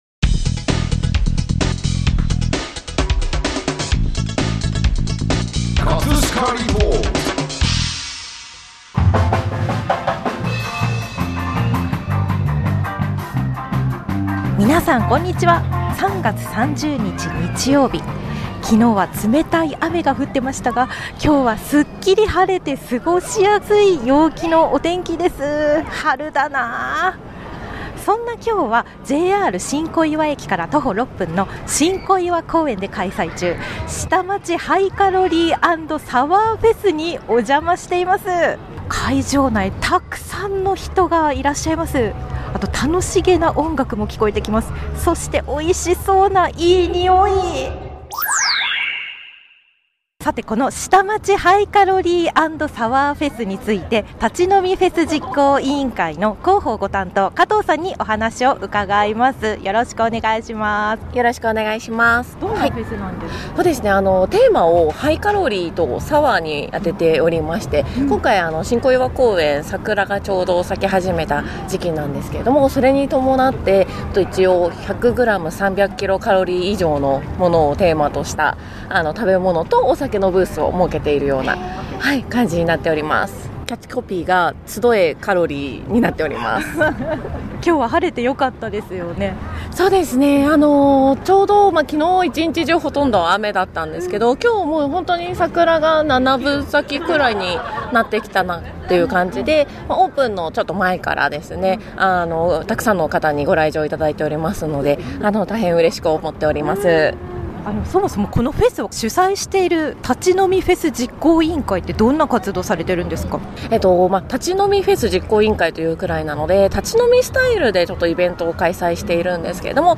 召し上がっているお客さんにインタビューもできましたよ♪
▼リポート音声